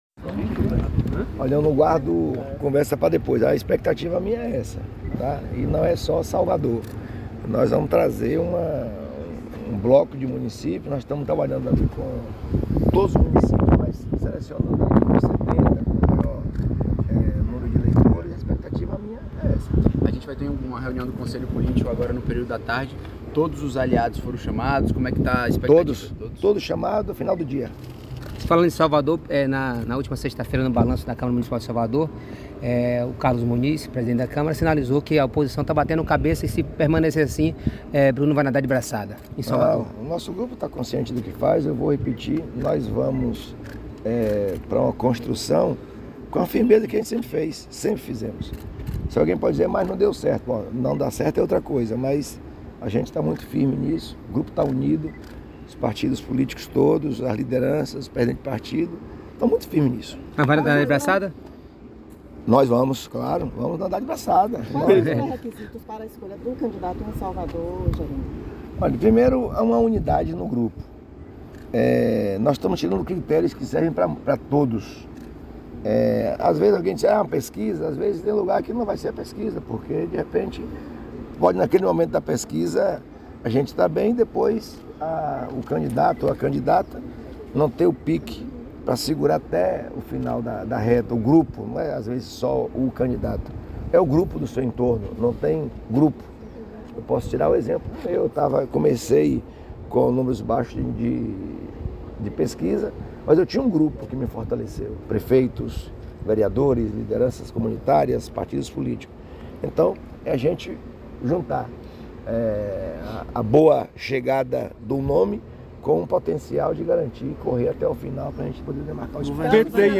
🎙Jerônimo Rodrigues – Governador da Bahia